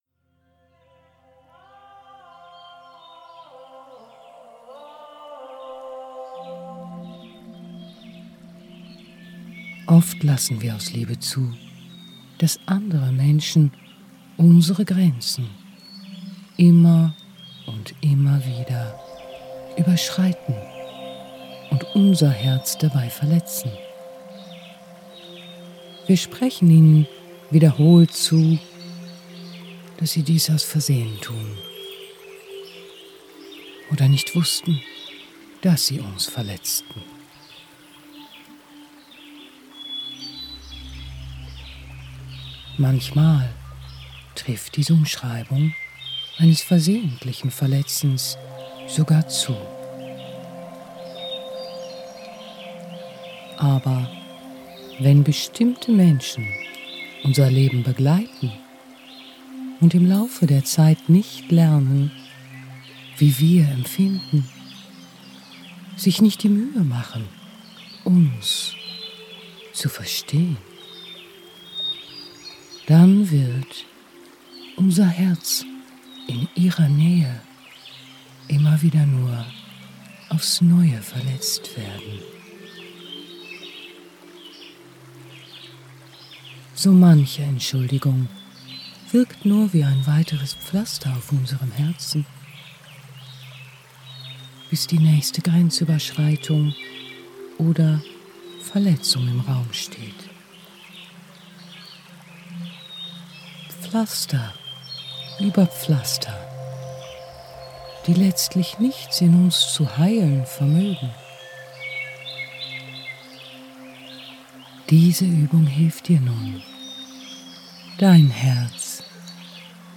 Durch die fein abgestimmte Kombination aus echten Naturklängen und heilsamen sphärischen Melodien erreichen Sie eine Veränderung der wissenschaftlich belegten Schwingungen in Ihrem Gehirn - von Beta-Wellen (38-15 Hz) zu Alpha-Wellen (14-8 Hz) hin zu Theta-Wellen (7-4 Hz).
Für einen optimalen Effekt empfehlen wir das Hören über Kopfhörer.